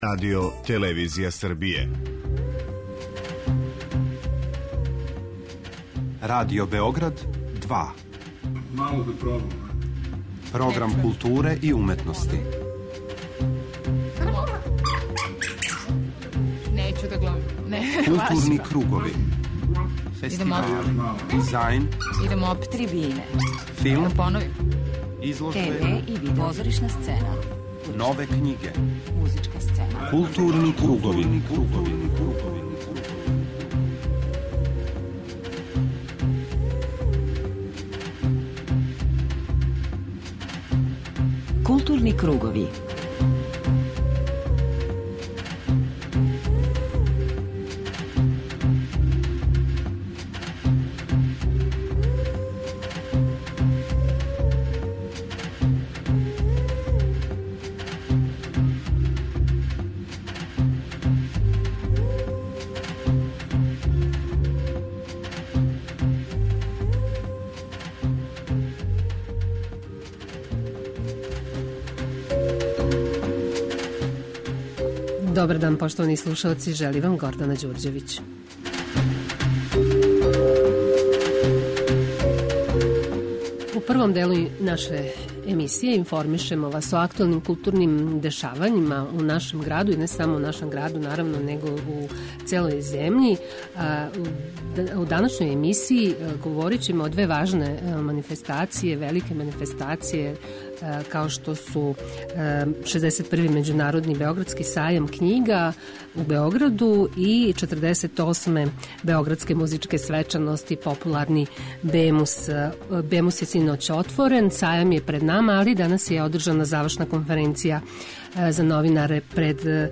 Гошћа данашег темата је глумица Анита Манчић.